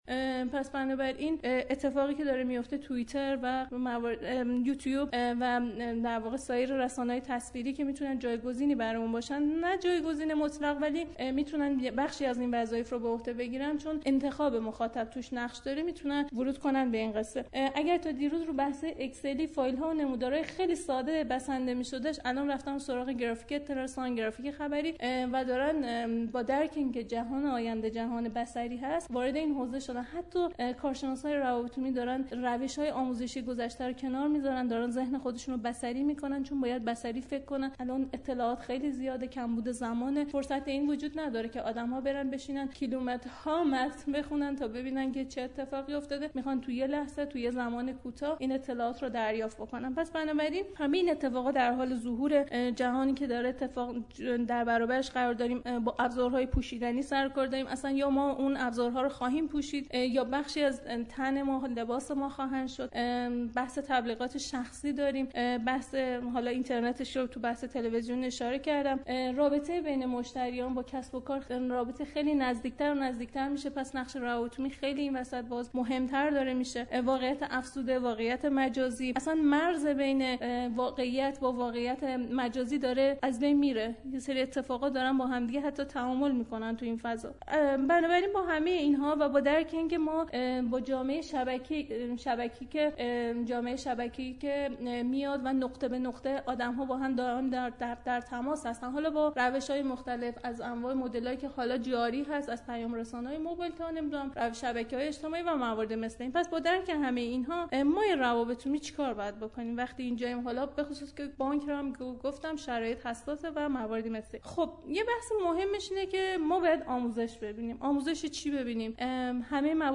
گفت و گو با رادیو روابط عمومی